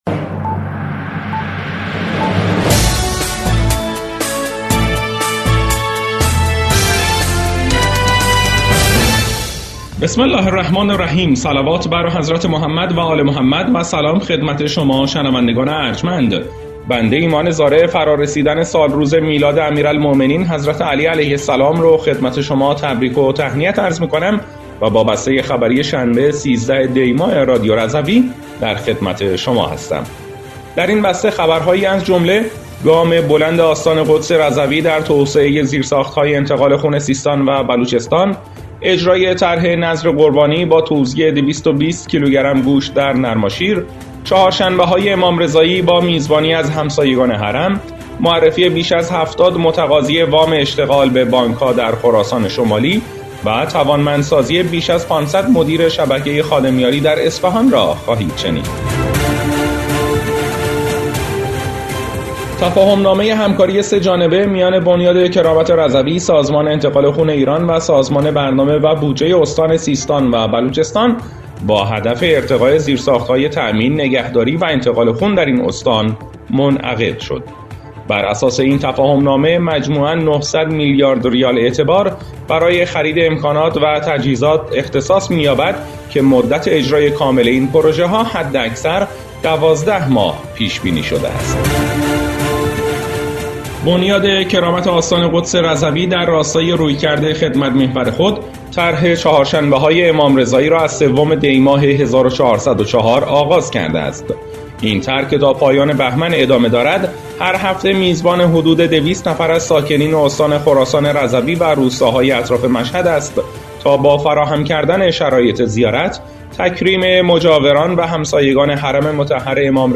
بسته خبری ۱۳ دی ۱۴۰۴ رادیو رضوی؛